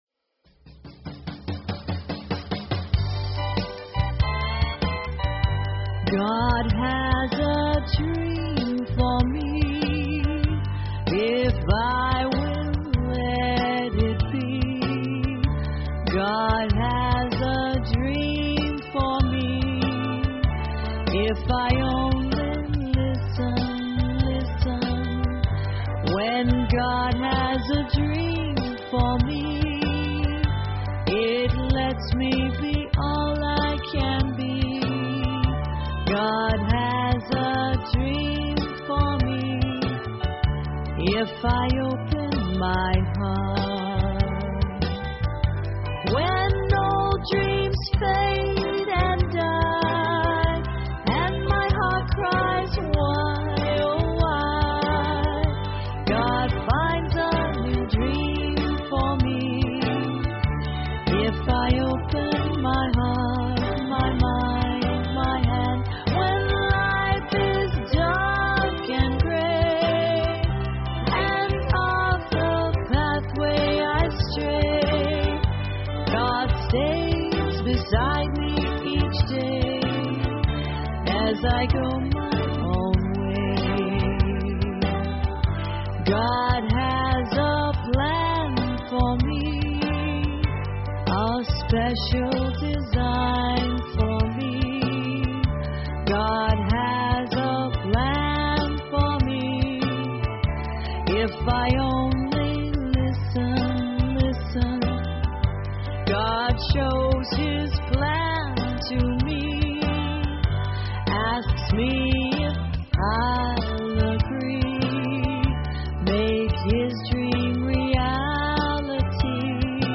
Talk Show Episode, Audio Podcast, Inner_Garden_Online_Chapel and Courtesy of BBS Radio on , show guests , about , categorized as